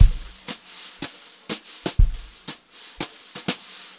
120JZBEAT4-L.wav